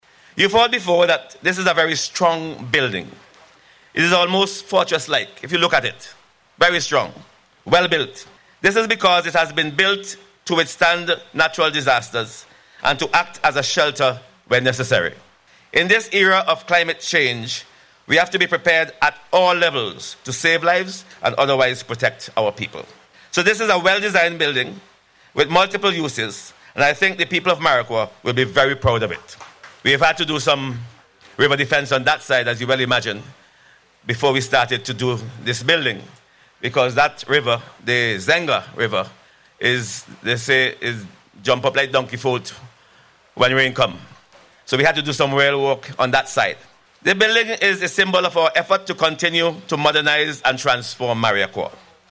This statement was made by Parliamentary Representative for the Marriaqua Constituency, St. Clair Prince during the ceremony which was held this past week, for the official opening of the Centre.